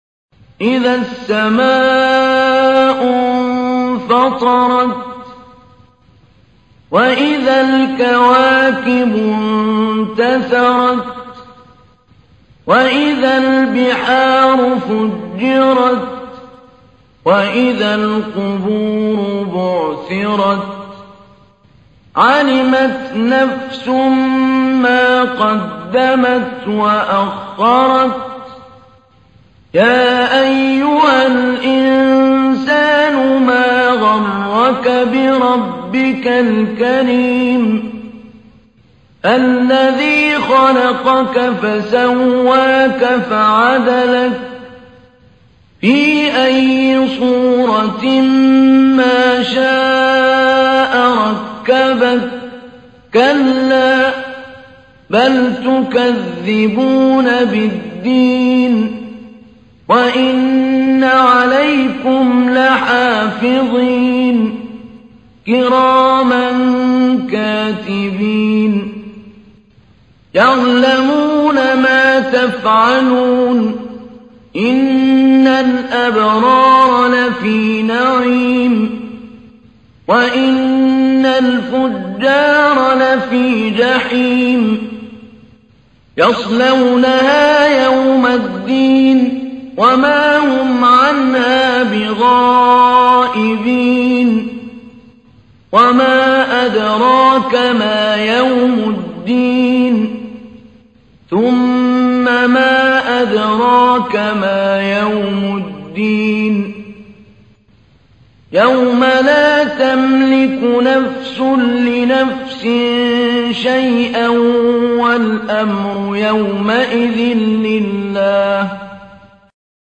تحميل : 82. سورة الانفطار / القارئ محمود علي البنا / القرآن الكريم / موقع يا حسين